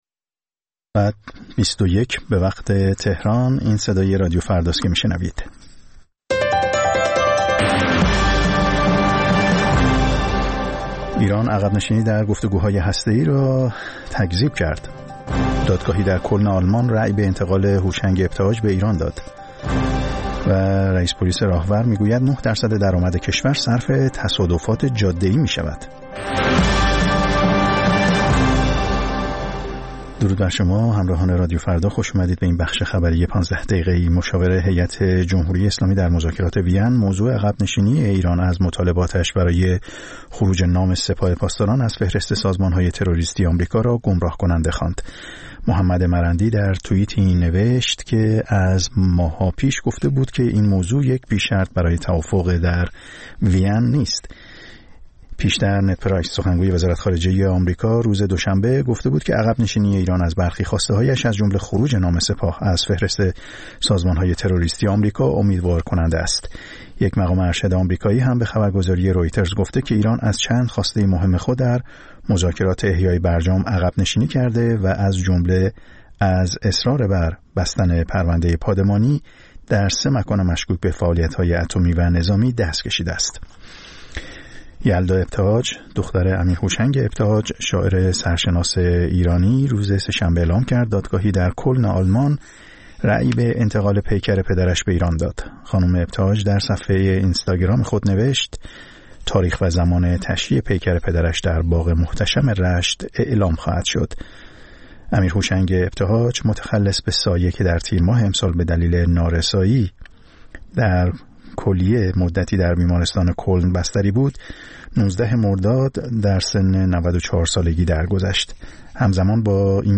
خبرها و گزارش‌ها ۲۱:۰۰